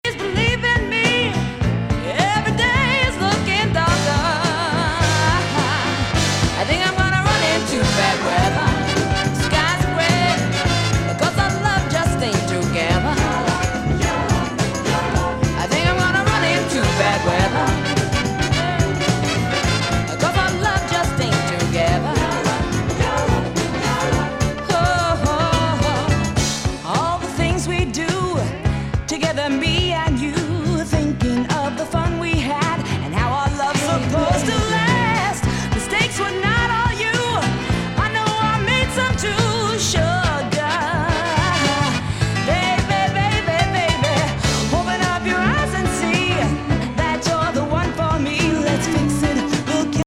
アンニュイ・メロウ・ソウル